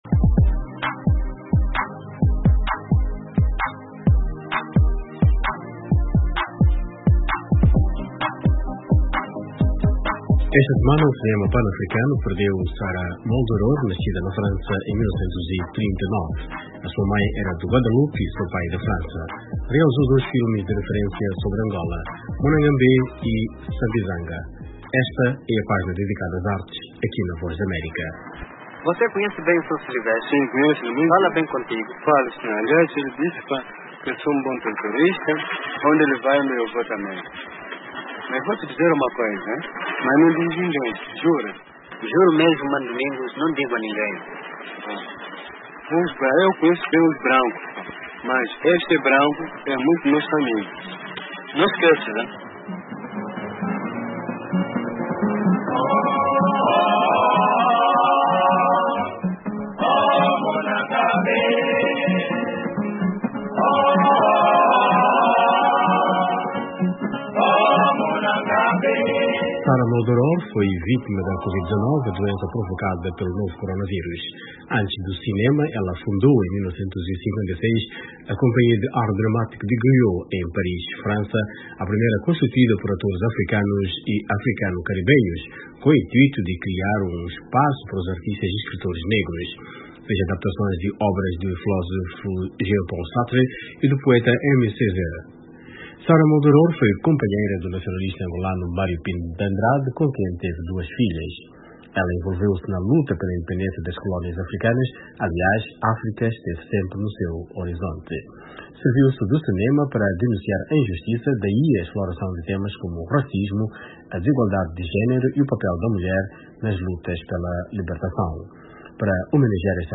Entrevistada pela VOA, a partir do Alentejo